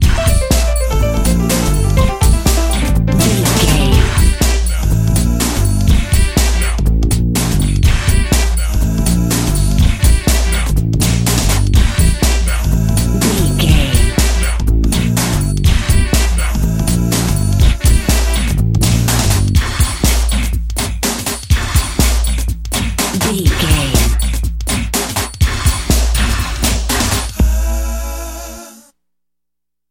Ionian/Major
D
synthesiser
drum machine
electric guitar
drums
strings
90s
Eurodance